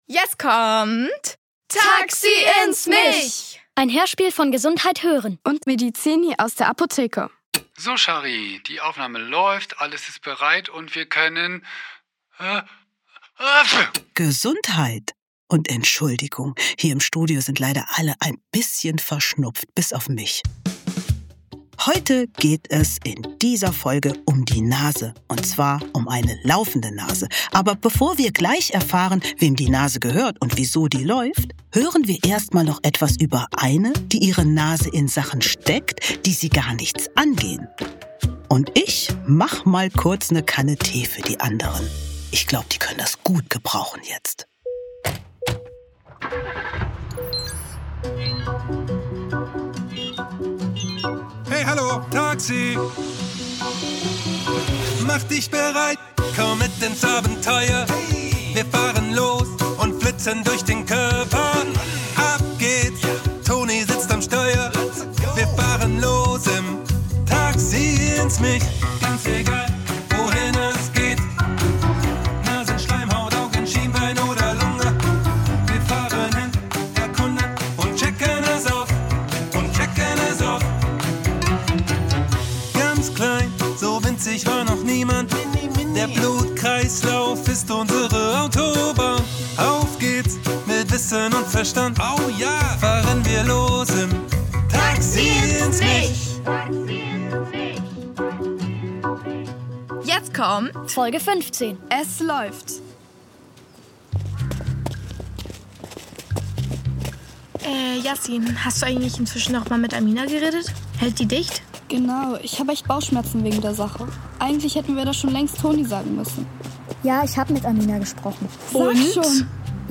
Es läuft! ~ Taxi ins Mich | Der Hörspiel-Podcast für Kinder Podcast